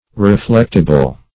Search Result for " reflectible" : The Collaborative International Dictionary of English v.0.48: Reflectible \Re*flect"i*ble\ (-?*b'l), a. Capable of being reflected, or thrown back; reflexible.